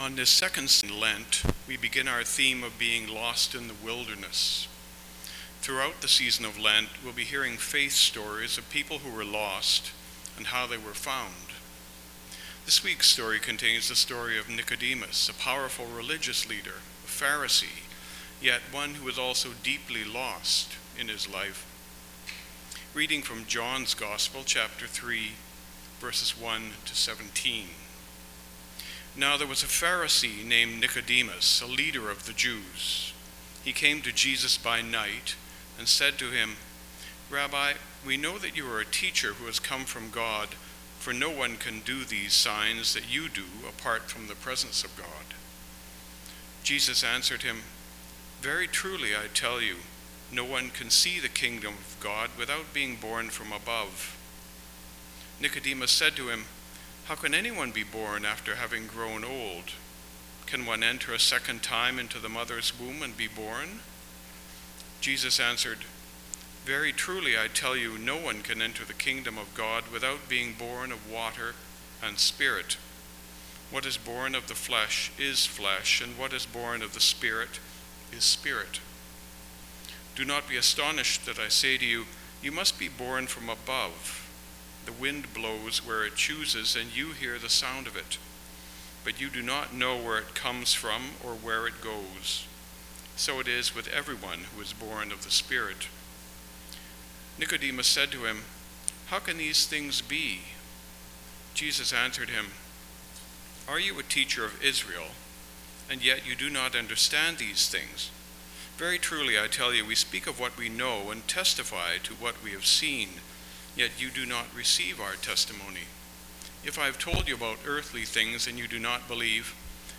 Sermons | Northwood United Church